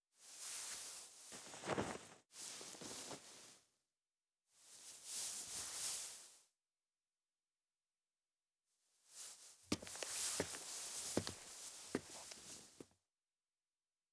胖子拖人_新.wav